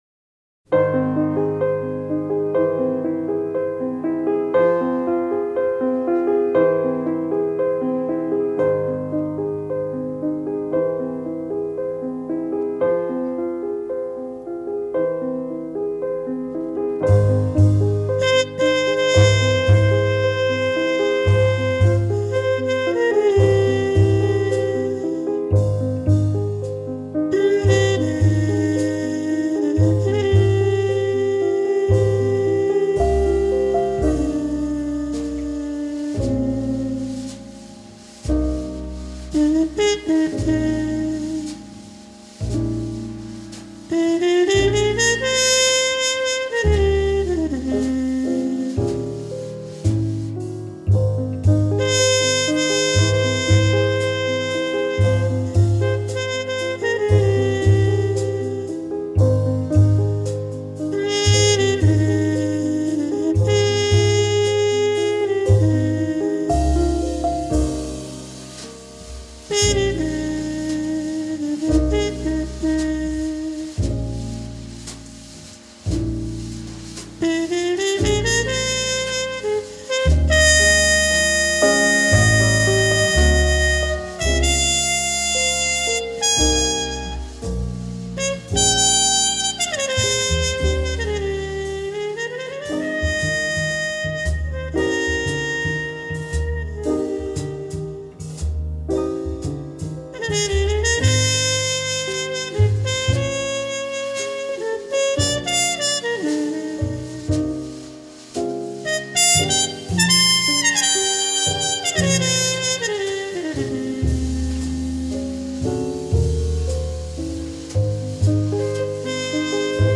爵士及藍調 (583)